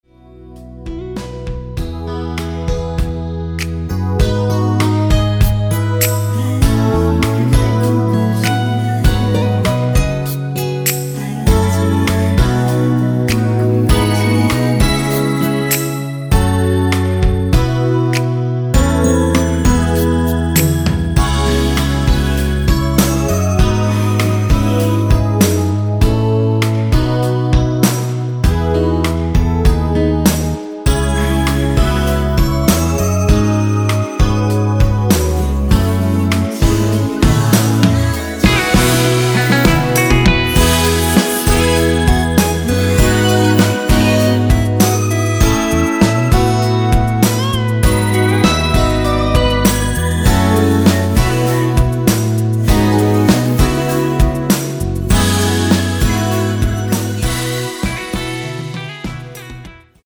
원키에서(+2)올린 코러스 포함된 MR입니다.
앞부분30초, 뒷부분30초씩 편집해서 올려 드리고 있습니다.